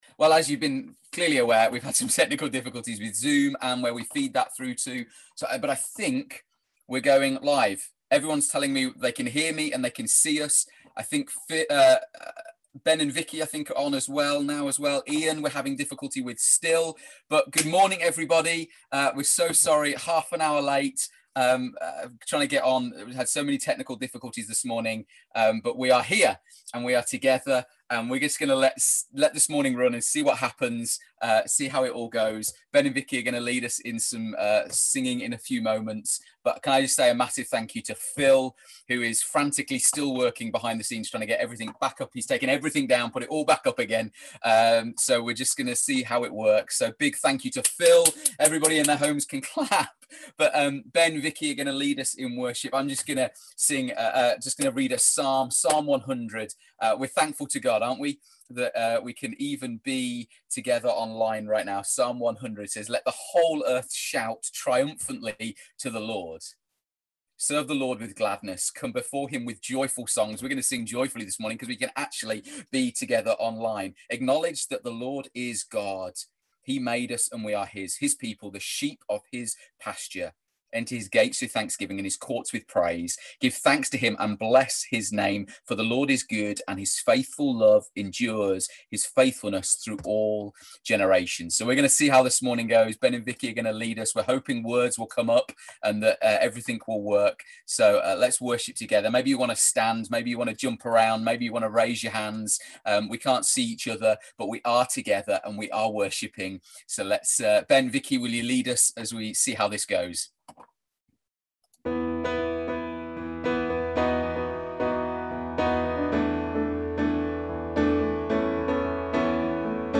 After some technical issues, we managed to gather for our weekly gathering featuring an amazing version of Goodness of God from our worship team.